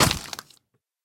mob / zombie / step2.ogg